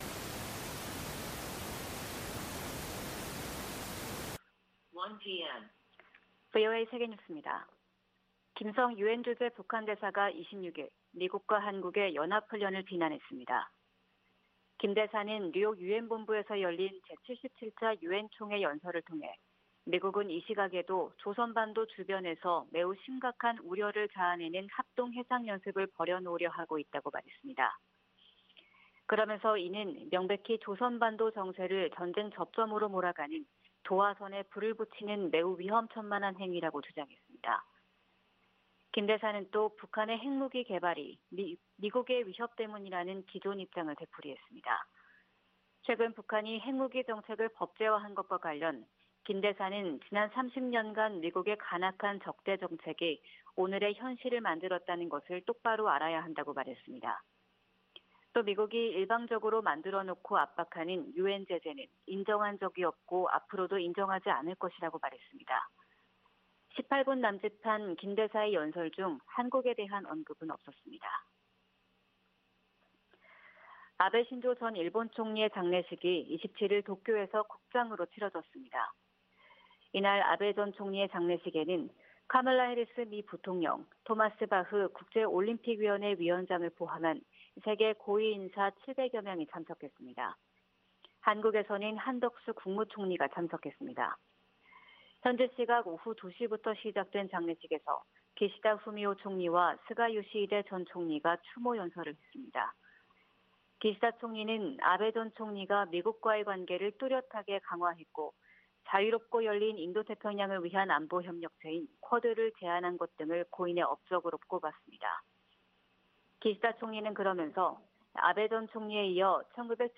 VOA 한국어 '출발 뉴스 쇼', 2022년 9월 28일 방송입니다. 미 국무부가 북한 정권의 어떤 도발도 한국과 일본에 대한 확고한 방어 의지를 꺾지 못할 것이라고 강조했습니다. 카멀라 해리스 미 부통령과 기시다 후미오 일본 총리가 회담에서 북한 정권의 탄도미사일 발사를 규탄했습니다. 북한이 핵 개발에 쓴 전체 비용이 최대 16억 달러에 달한다는 분석 결과가 나왔습니다.